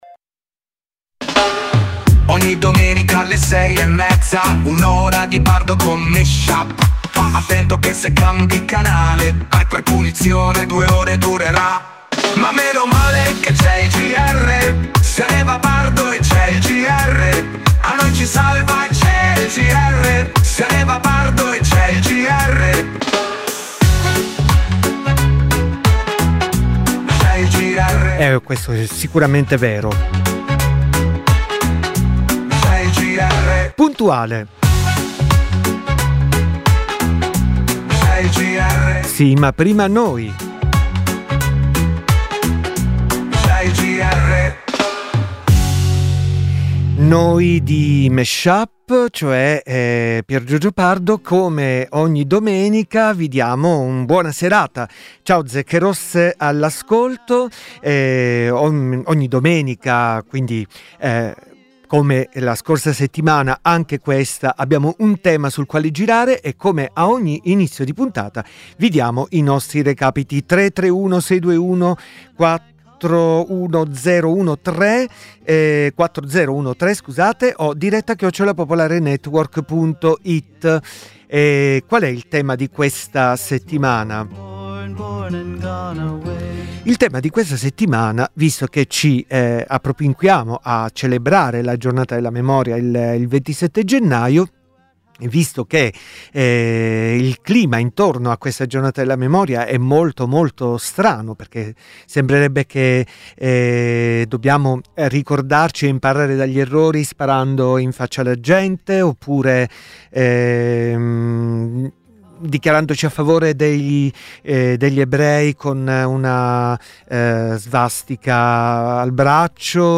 Ogni settimana un dj set tematico di musica e parole